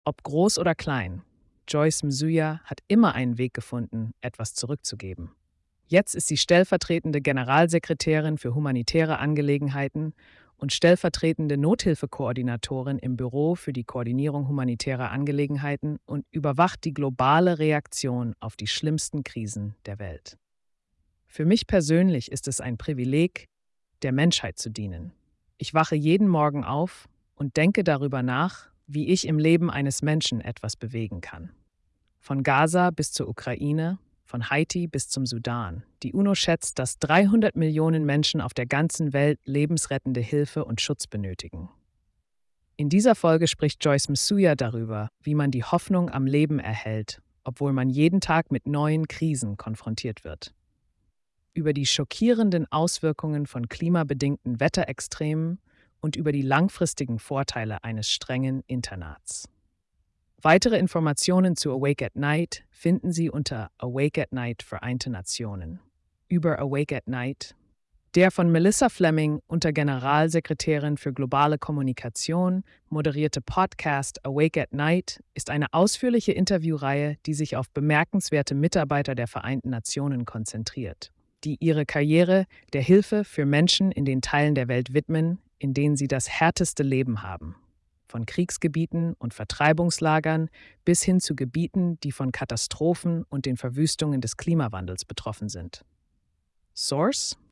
Der von Melissa Fleming, Untergeneralsekretärin für globale Kommunikation, moderierte Podcast ‚Awake at Night‘ ist eine ausführliche Interviewreihe, die sich auf bemerkenswerte Mitarbeiter der Vereinten Nationen konzentriert, die ihre Karriere der Hilfe für Menschen in den Teilen der Welt widmen, in denen sie das härteste Leben haben – von Kriegsgebieten und Vertreibungslagern bis hin zu Gebieten, die von Katastrophen und den Verwüstungen des Klimawandels betroffen sind.